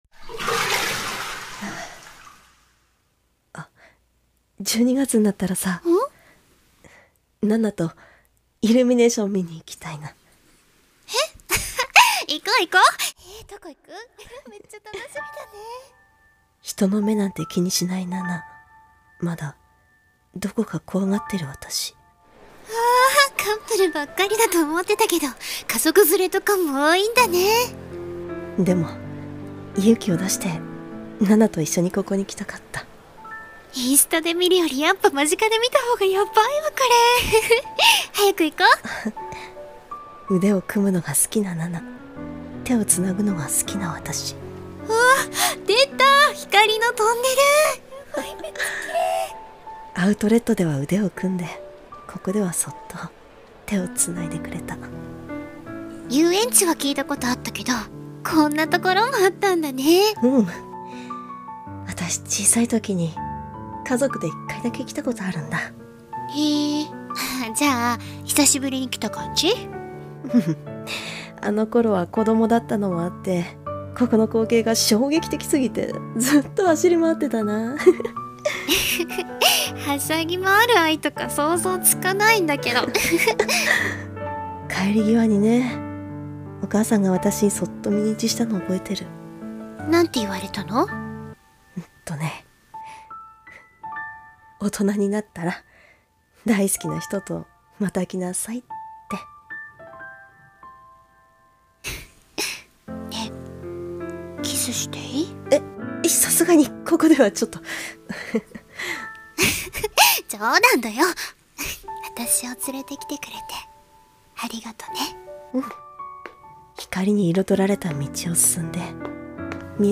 百合 in イルミネーション【2人声劇】